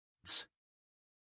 us_phonetics_sound_cats.mp3